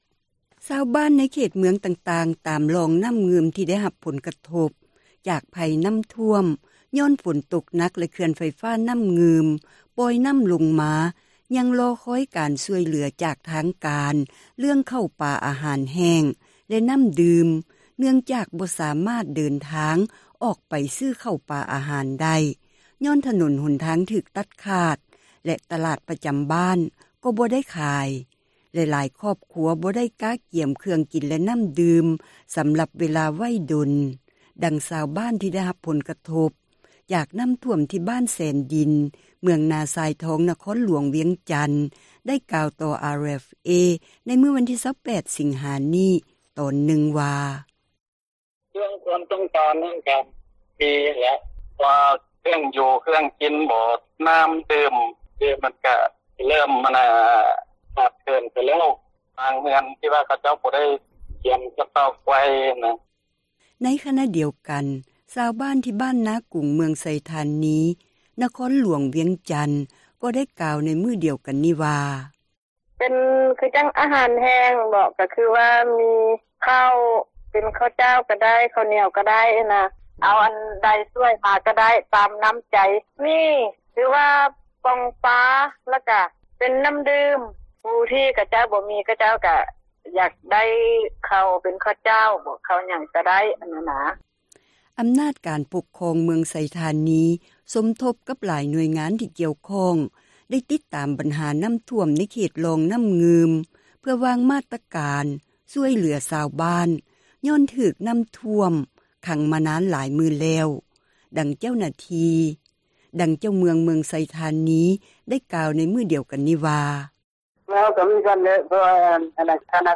ໃນຂນະດຽວກັນ ຊາວບ້ານ ທີ່ບ້ານນາກຸງ ເມືອງໄຊທານີ ນະຄອນຫລວງວຽງຈັນ ກໍໄດ້ກ່າວໃນມື້ດຽວກັນນີ້ວ່າ: